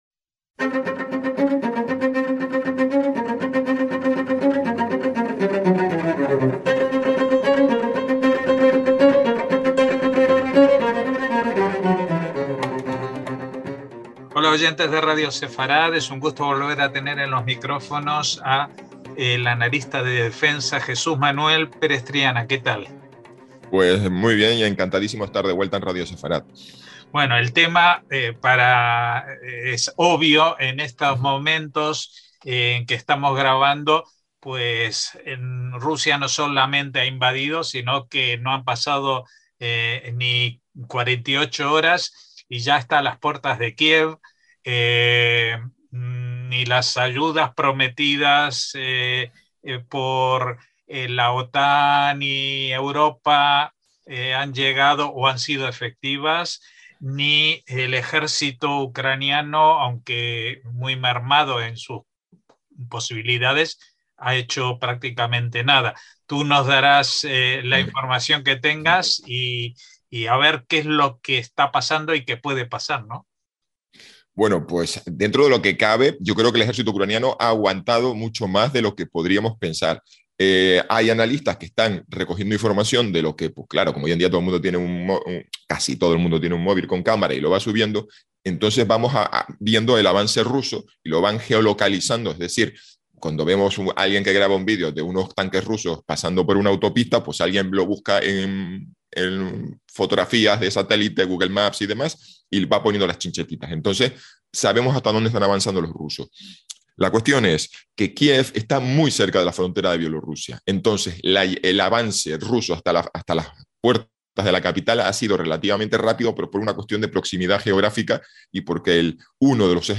Una entrevista sin pérdida para orientarse en tiempos de confusión.